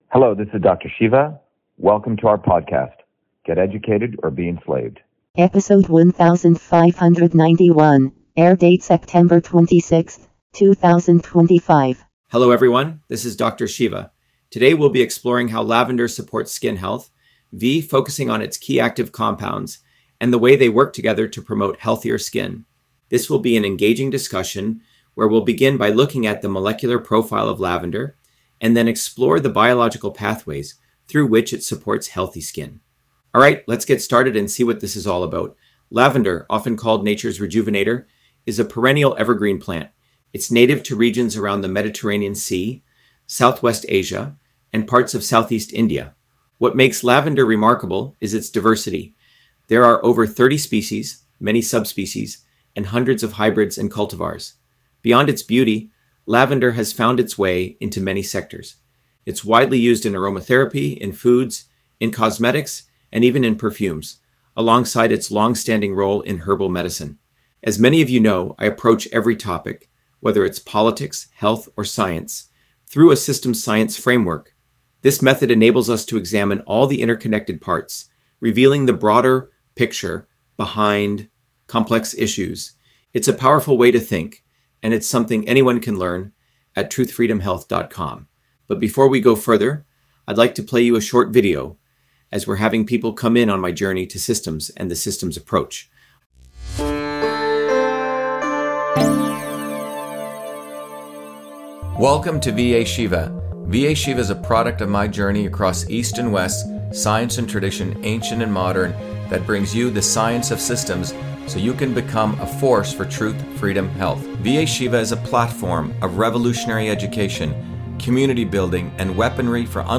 In this interview, Dr.SHIVA Ayyadurai, MIT PhD, Inventor of Email, Scientist, Engineer and Candidate for President, Talks about Lavender on Skin Health: A Whole Systems Approach